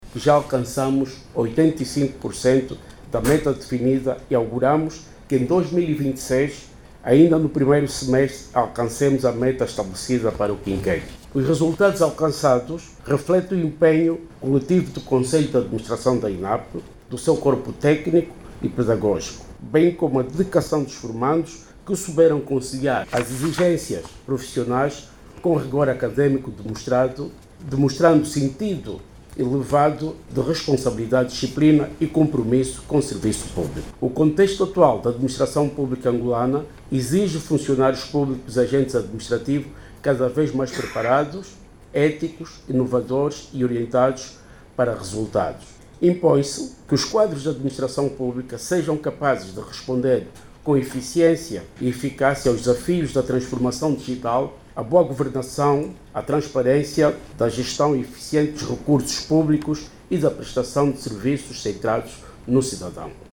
A informação foi avançada pelo Secretário de Estado da Administração Pública, Trabalho e Segurança Social, Domingos Felipe, durante o acto de encerramento do ano lectivo 2025 da Escola Nacional de Administração e Políticas Públicas – ENAPP.